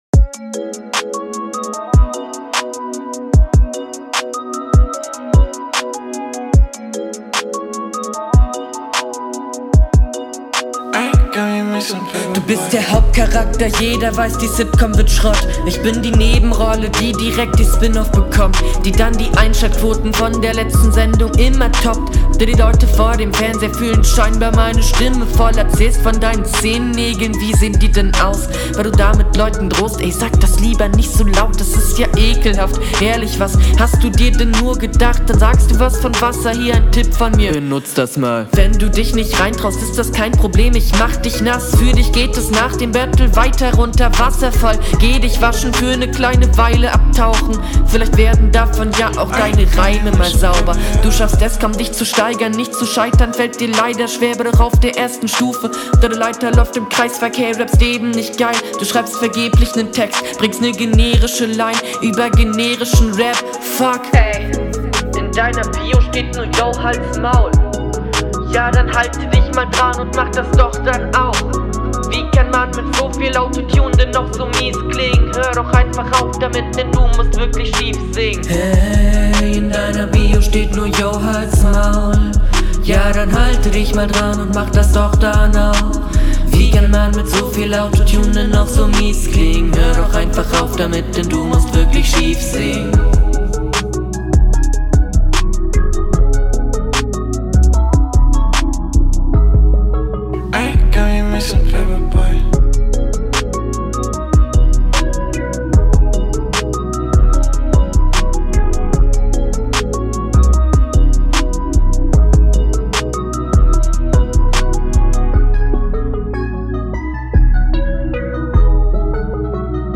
Flow: War schief.
Flow: Er wirkt hier um einiges unsicherer.